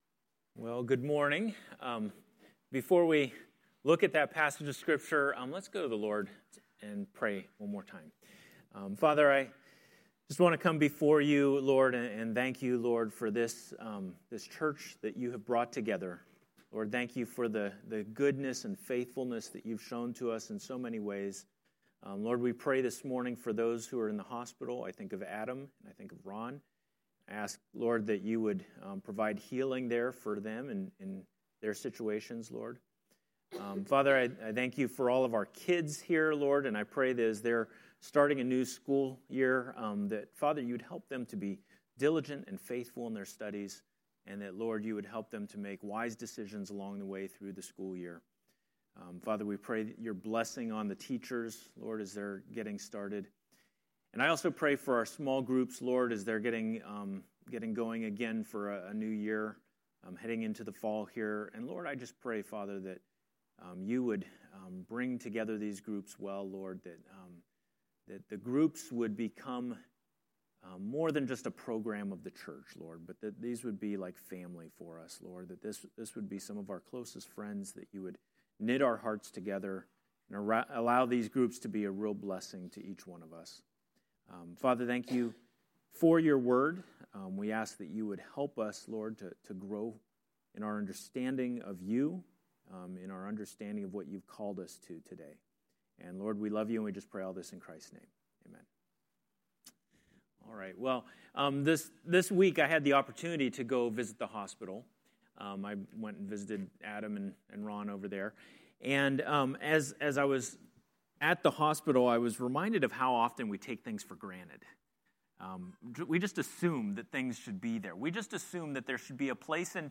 A message from the series "Discipleship Essentials."